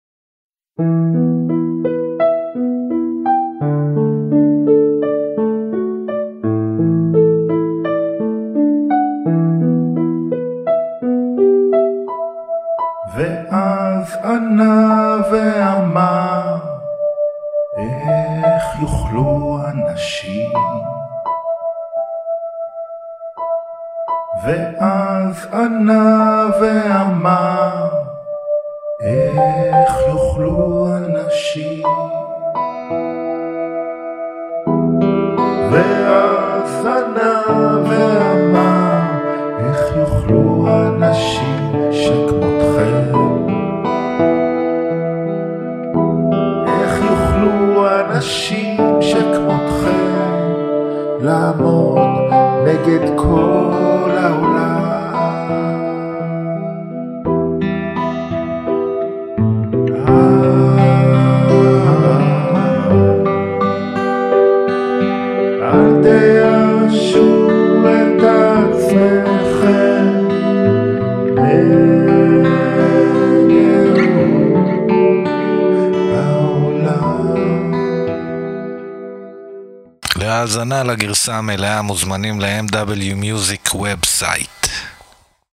הרכב רוק־אינדי עברי חד, חצוף ועמוק